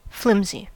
Ääntäminen
IPA: [ʃvaχ]